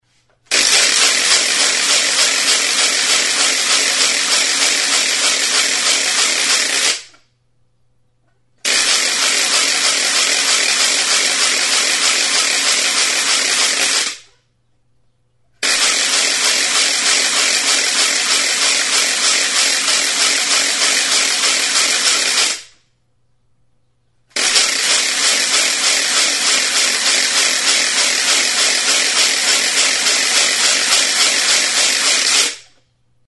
Idiophones -> Frappés -> Système de la crécelle
Enregistré avec cet instrument de musique.
Mihi bateko karraka arrunta.
BOIS